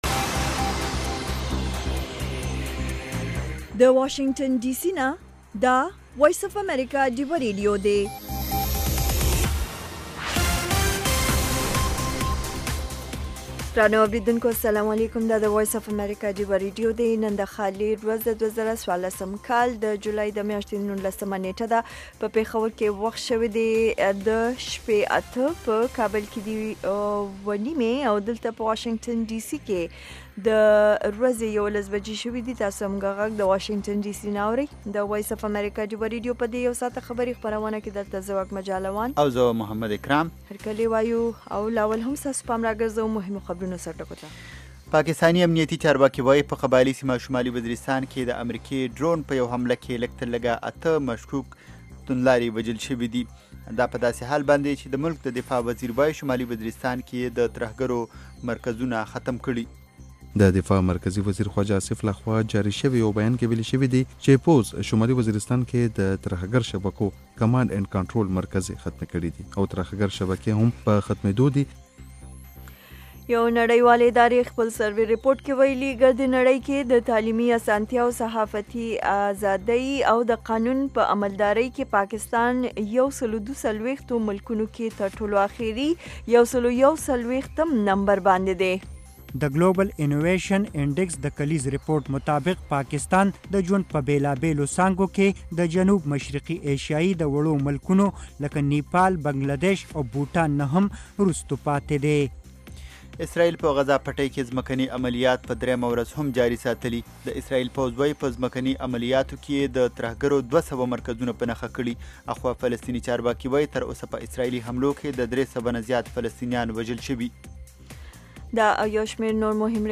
خبرونه - 1500